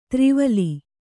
♪ tri vali